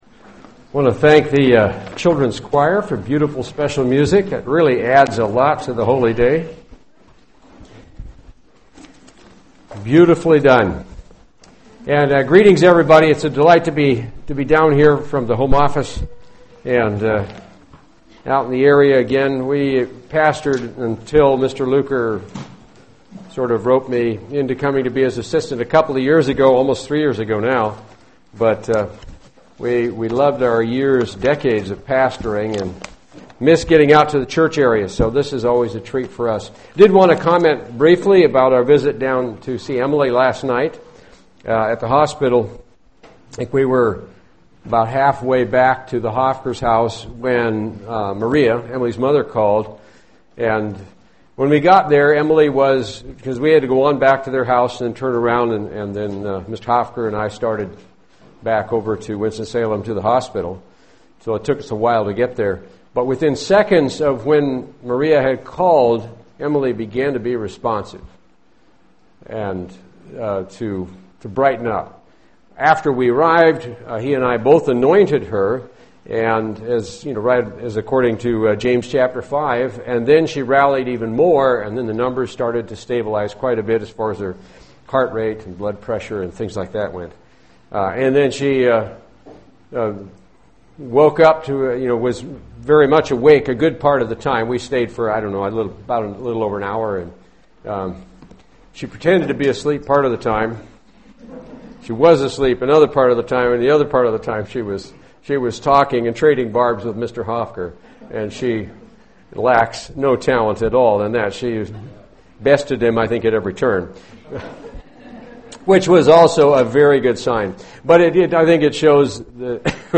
Given in Charlotte, NC
UCG Sermon Studying the bible?